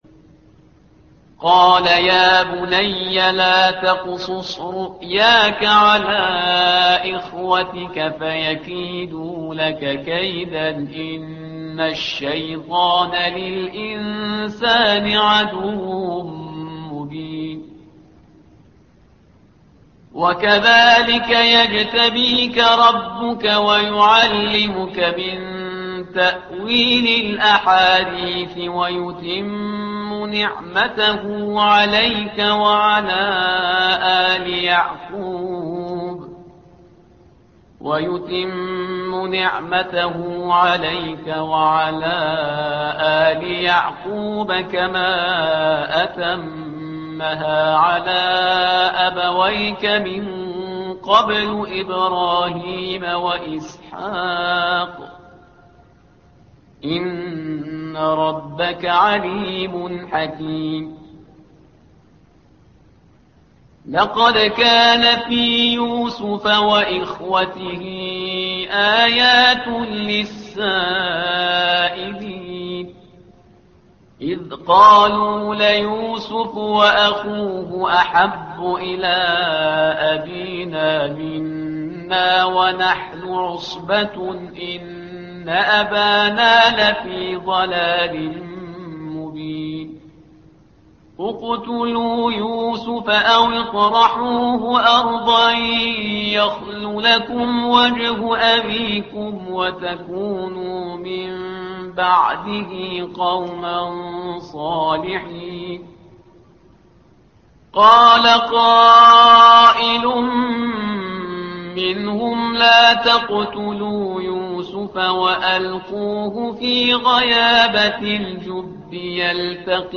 الصفحة رقم 236 / القارئ